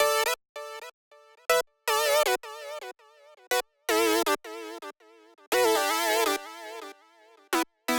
34 5th Synth PT1.wav